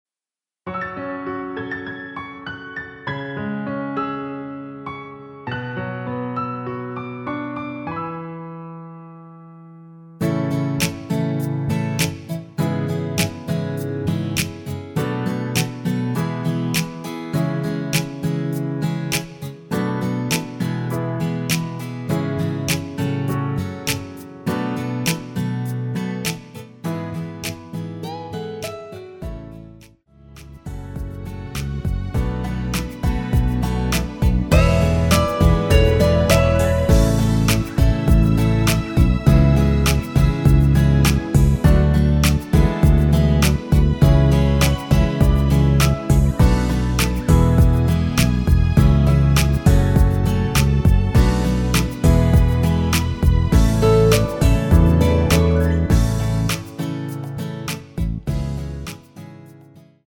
원키에서(+6)올린 MR입니다.
여성분 혼자서 부르실수 있는 키로 제작 하였습니다.
앞부분30초, 뒷부분30초씩 편집해서 올려 드리고 있습니다.